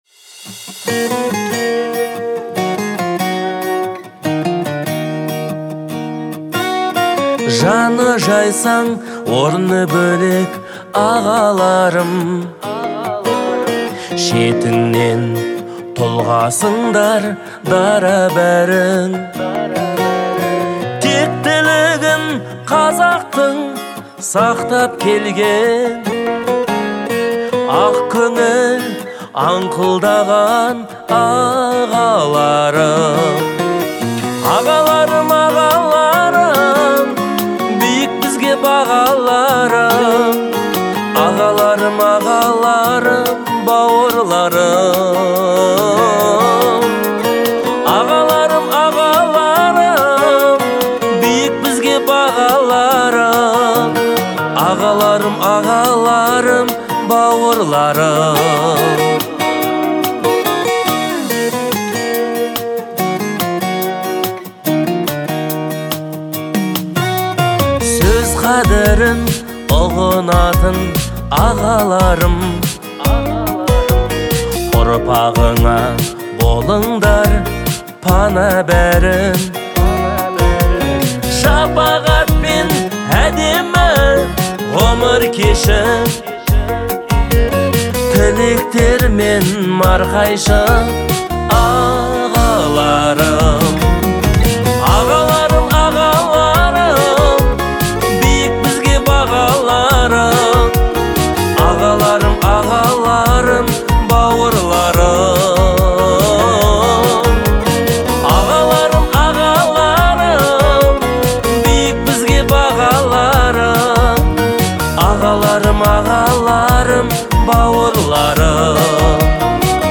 трек в жанре поп с элементами народной музыки